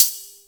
CHINA CYMBAL